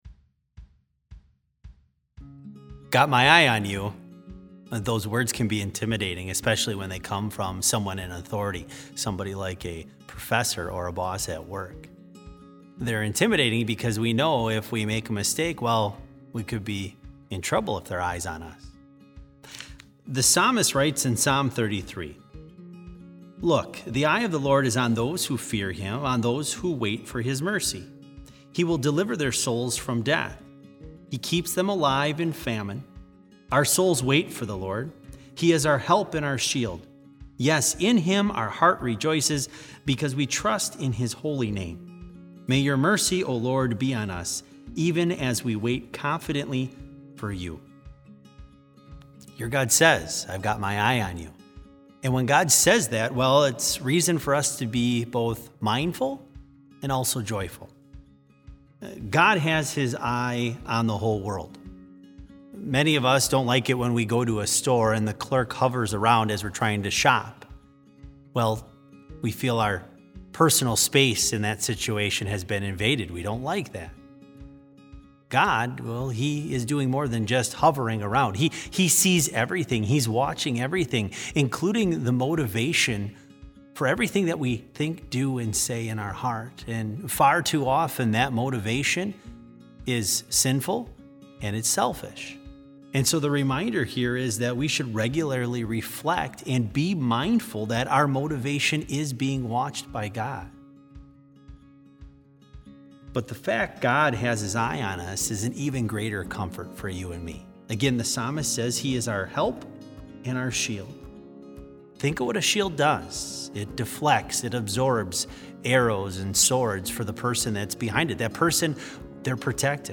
Complete service audio for BLC Devotion - April 23, 2020